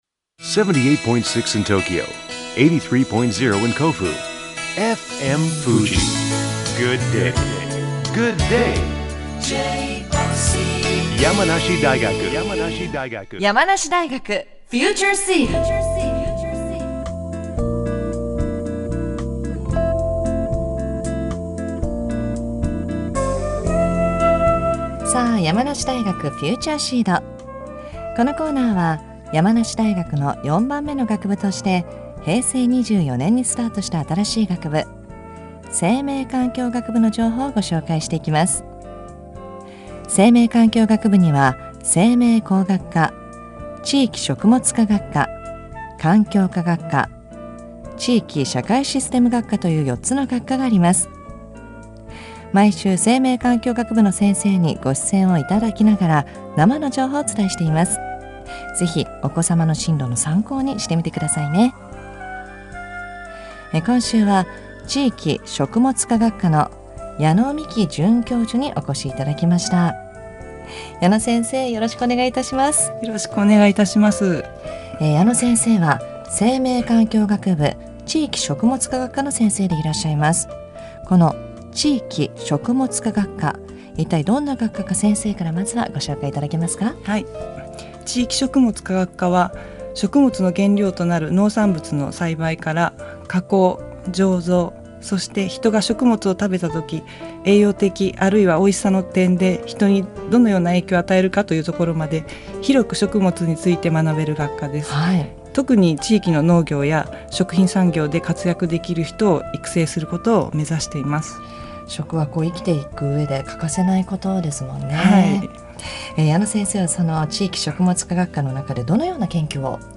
1月7日放送分